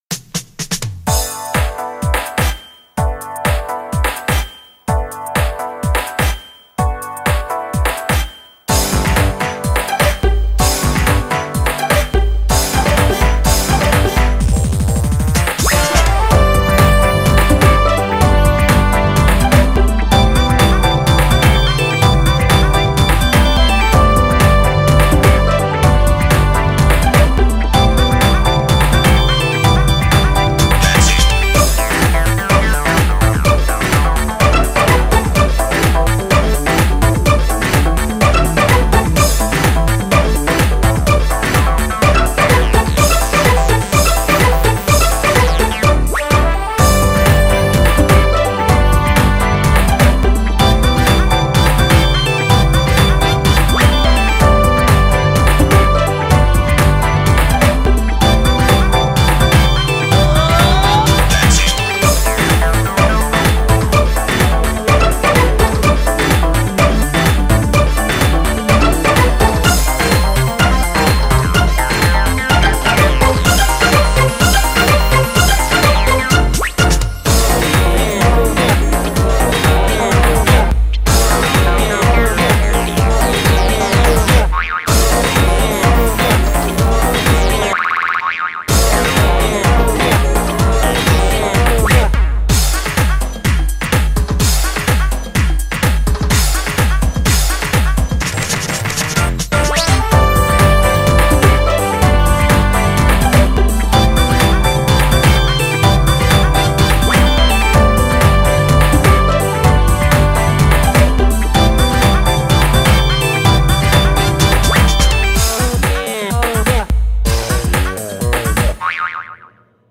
BPM126
Audio QualityPerfect (High Quality)
Fairly repetitive song with lots of stops, be weary!